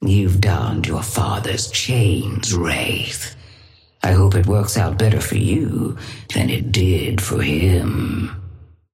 Sapphire Flame voice line - You've donned your father's chains, Wraith. I hope it works out better for you than it did for him.
Patron_female_ally_wraith_start_04.mp3